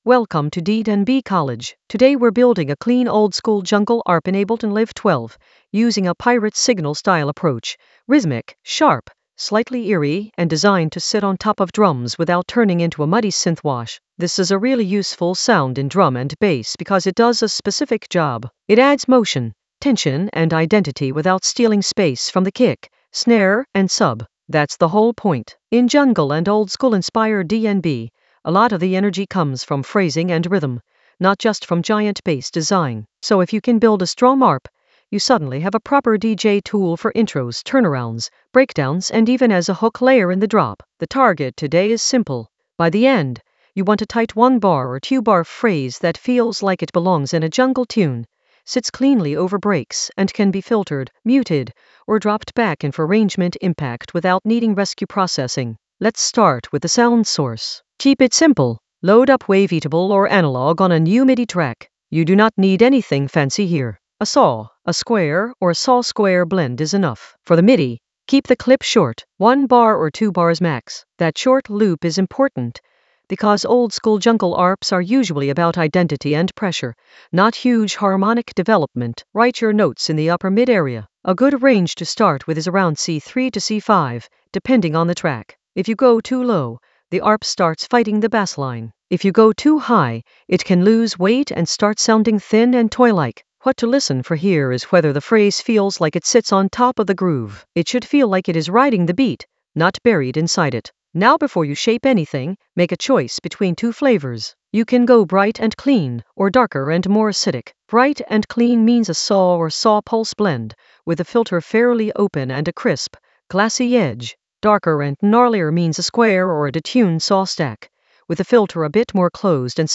An AI-generated beginner Ableton lesson focused on Pirate Signal approach: oldskool DnB jungle arp clean in Ableton Live 12 in the DJ Tools area of drum and bass production.
Narrated lesson audio
The voice track includes the tutorial plus extra teacher commentary.